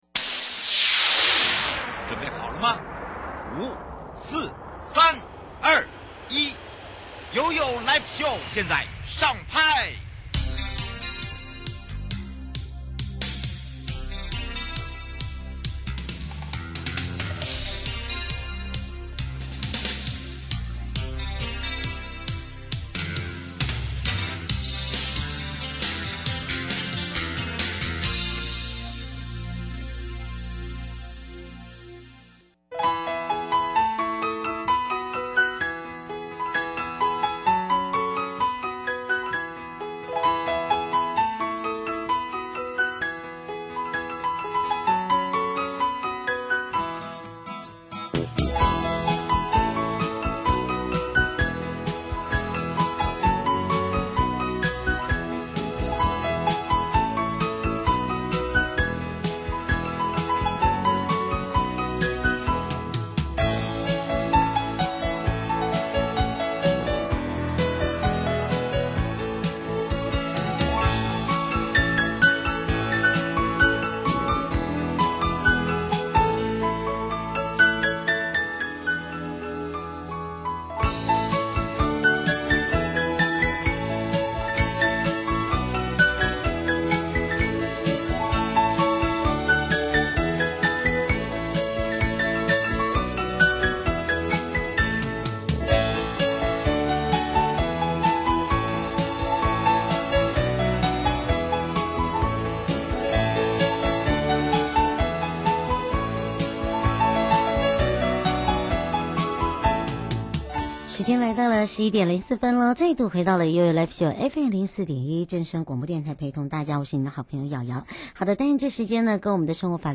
受訪者： 台北地檢 許祥珍主任檢察官 台北地檢署 邢泰釗檢察長 節目內容： 錢匯錯到別人的金融帳號，別人一直沒有歸還，是否構成犯罪？